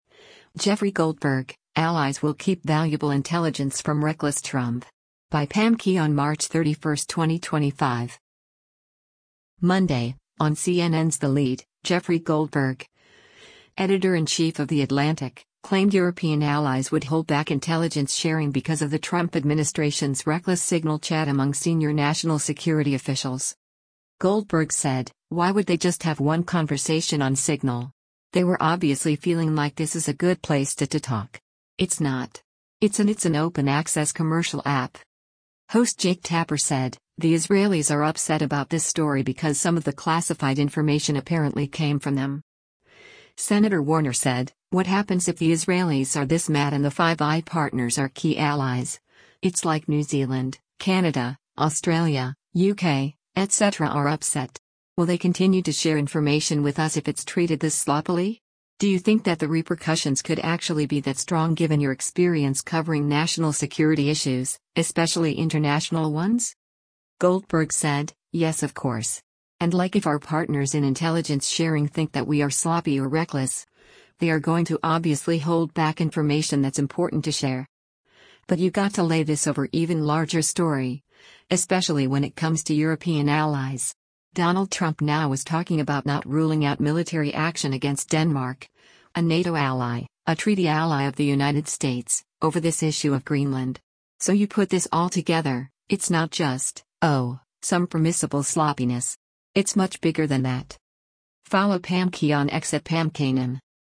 Monday, on CNN’s “The Lead,” Jeffrey Goldberg, editor-in-chief of The Atlantic, claimed European allies would hold back intelligence sharing because of the Trump administration’s “reckless” Signal chat among senior national security officials.